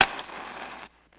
Lighting Fuse, Some Burning